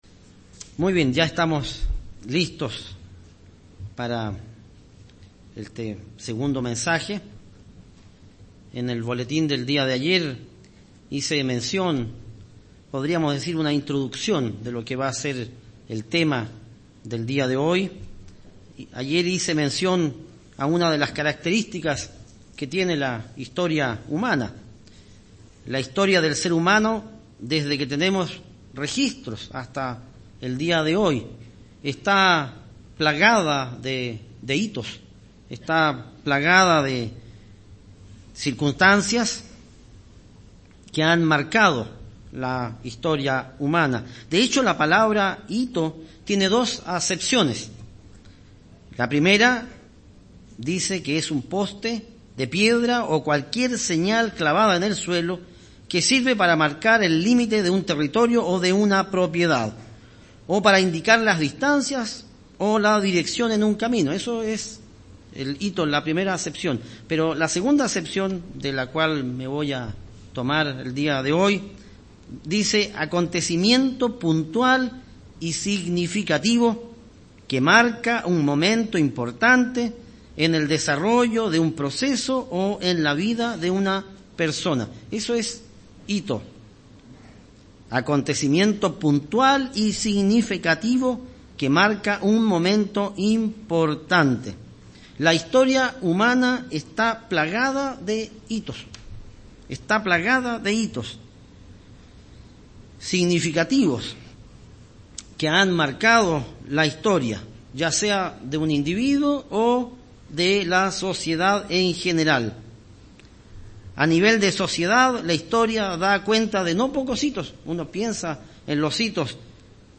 Existieron puntos de inflexión que marcaron el desarrollo de la humanidad y de la Iglesia de Dios... y que lo harán también en un futuro. Mensaje entregado el 26 de Julio de 2017.